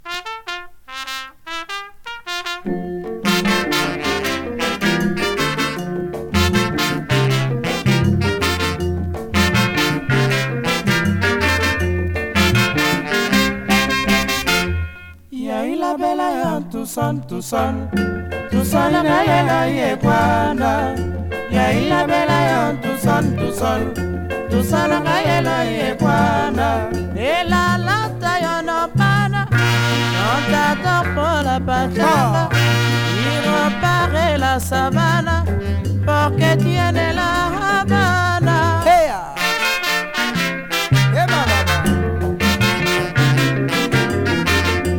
熱風とか地熱を感じるかのような心地良さと、独特の浮遊感を錯覚しそうな素晴らしさ。